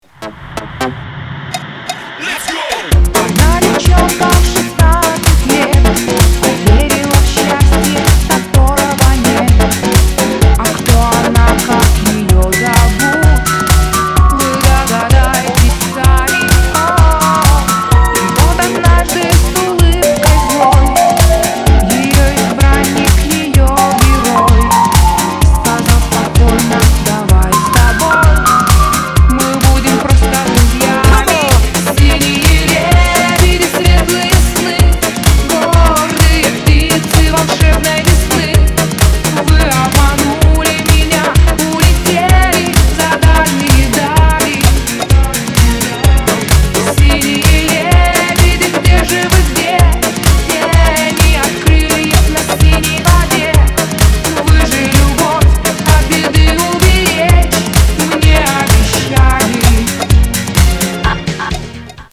• Качество: 256, Stereo
dance